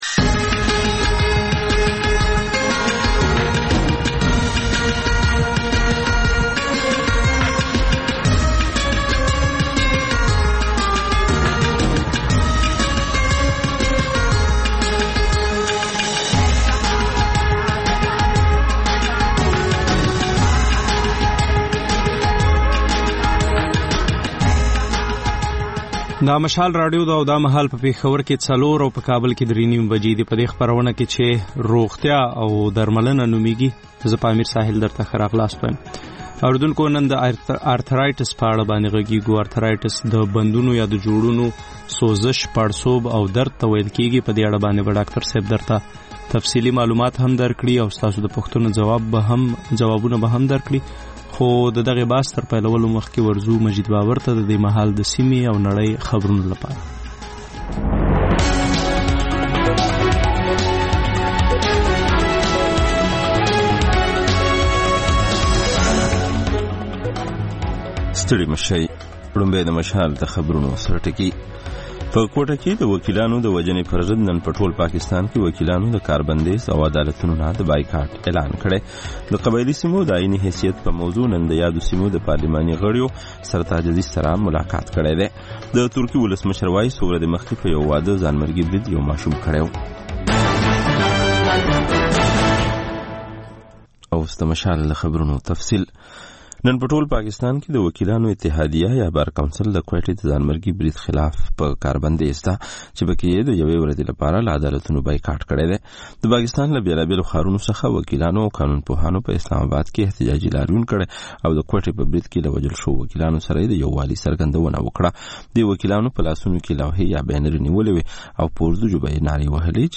د مشال راډیو مازیګرنۍ خپرونه. د خپرونې پیل له خبرونو کېږي. د دوشنبې یا د ګل پر ورځ د روغتیا په اړه ژوندۍ خپرونه روغتیا او درملنه خپرېږي چې په کې یو ډاکتر د یوې ځانګړې ناروغۍ په اړه د خلکو پوښتنو ته د ټیلي فون له لارې ځواب وايي.